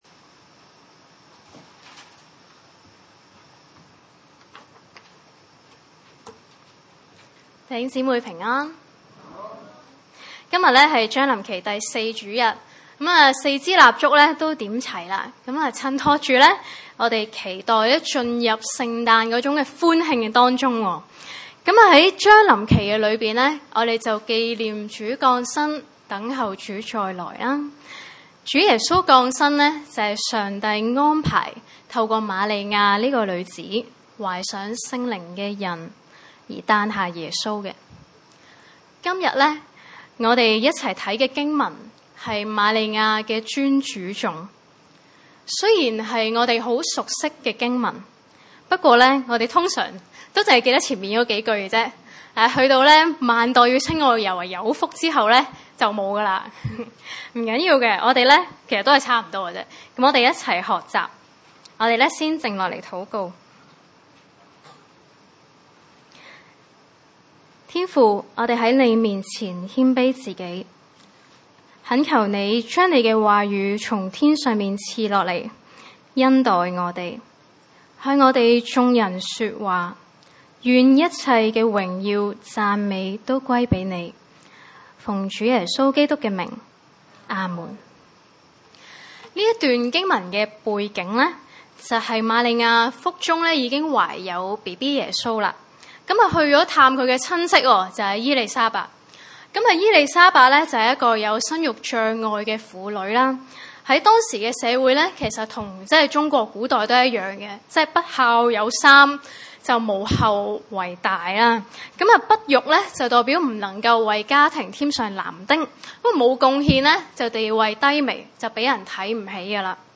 路1:46-55 崇拜類別: 主日午堂崇拜 46.